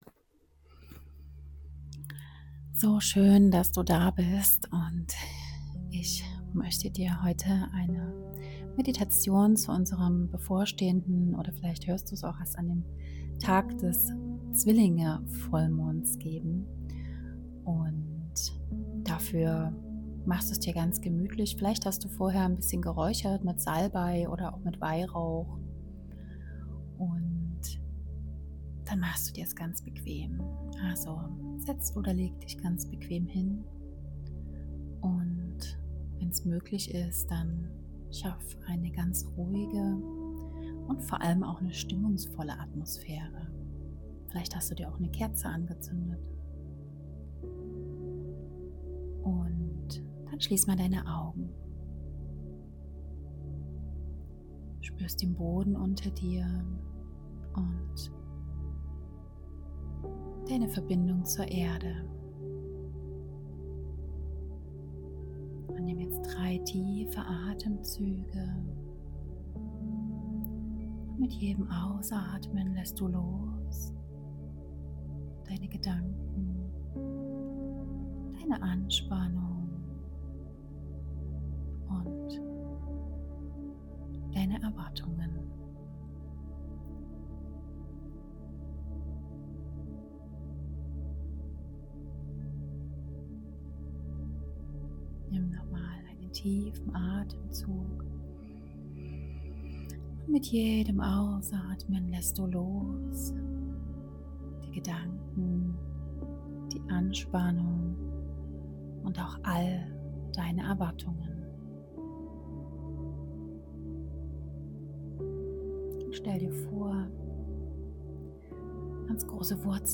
Diese geführte Meditation hilft dir: